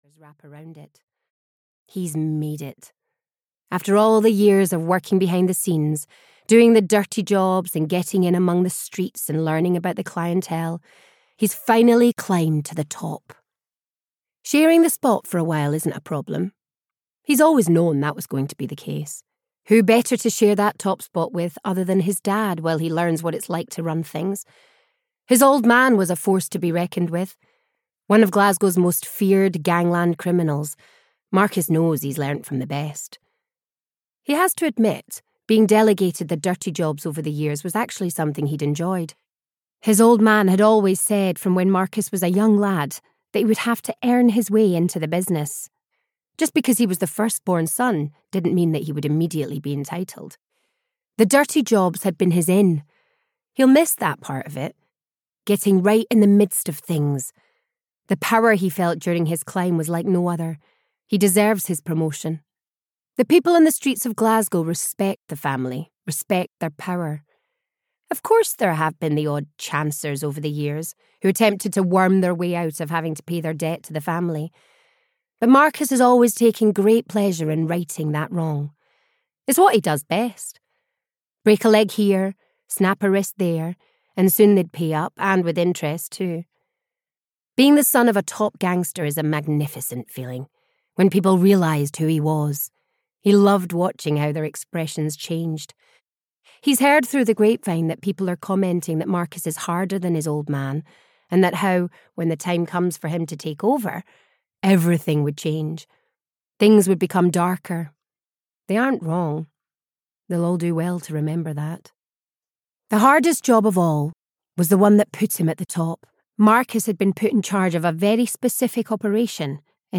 The Angels (EN) audiokniha
Ukázka z knihy